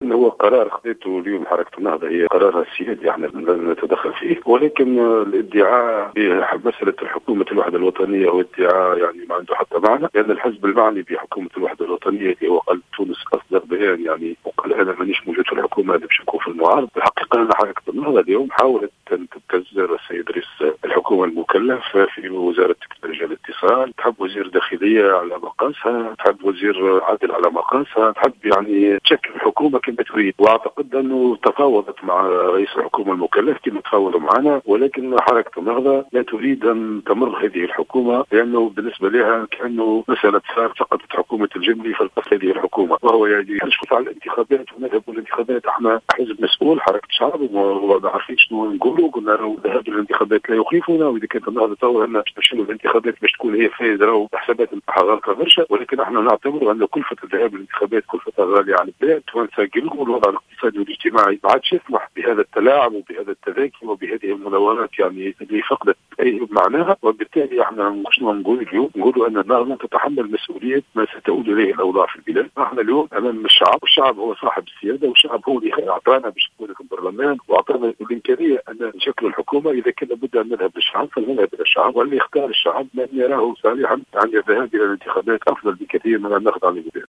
انتقد الأمين العام لحركة الشعب زهير المغراوي في تصريح لـ "الجوهرة أف أم" مساء اليوم التعطيلات التي طالت مشاورات تشكيل حكومة الياس الفخفاخ. واعتبر أن الذهاب إلى انتخابات تشريعية جديدة أفضل من الخضوع لابتزاز حركة النهضة، وفق ترجيحه، مضيفا ان الحركة تدفع البلاد نحو أزمة خطيرة بعد اعلانها الانسحاب من حكومة الفخفاخ وعدم منحها الثقة.